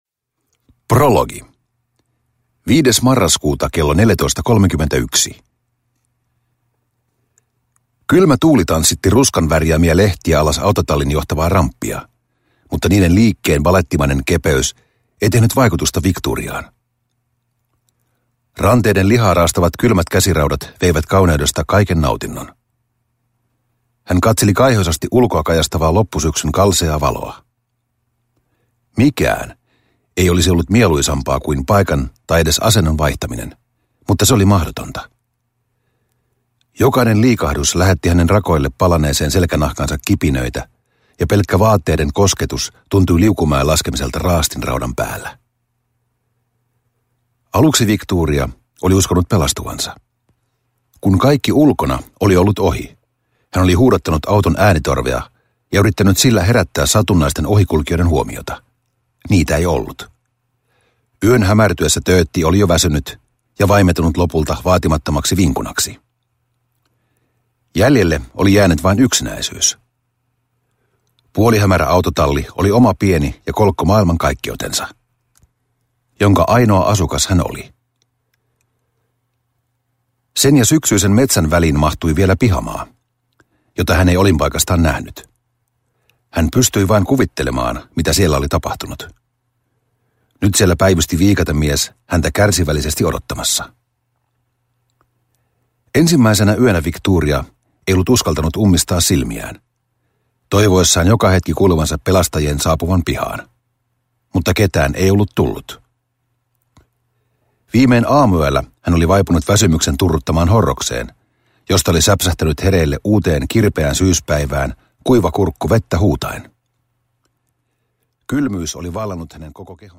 Tappava formaatti: Kuokkavieras – Ljudbok – Laddas ner